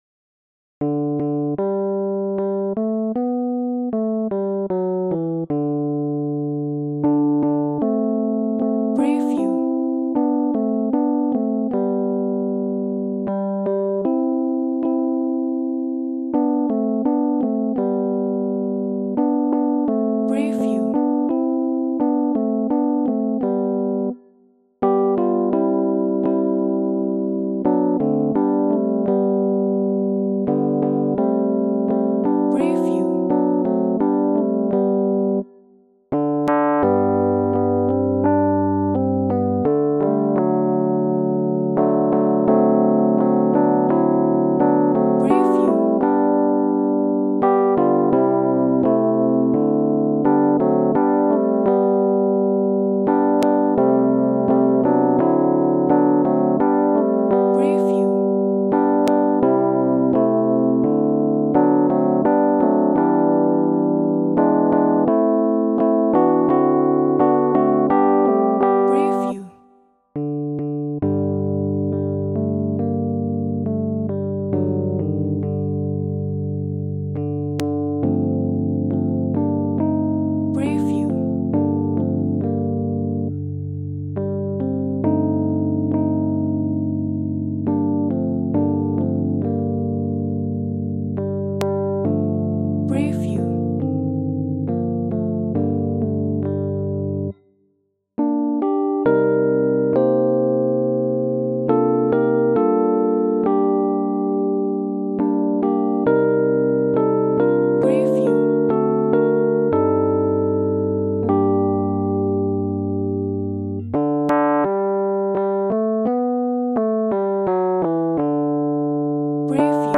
Вид: TTBB Жанр